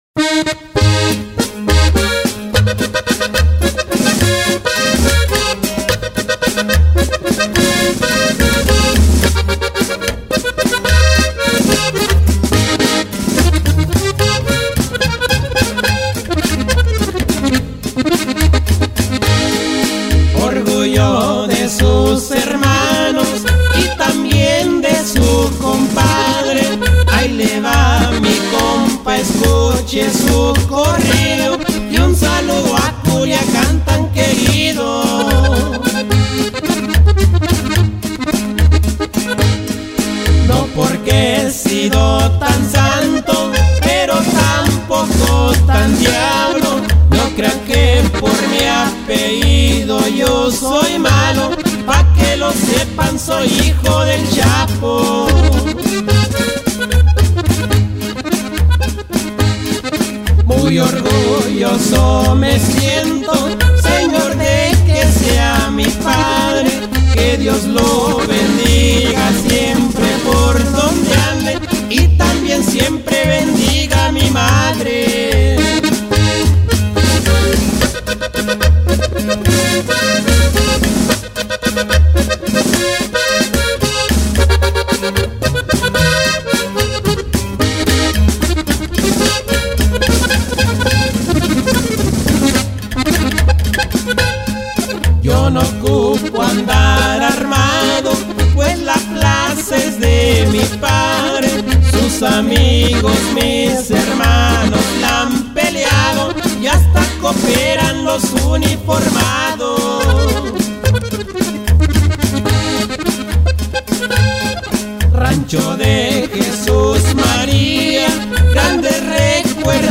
ACORDEONISTA Y SEGUNDA VOZ
BAJOSEXTO Y PRIMERA VOZ
TAROLA
TUBA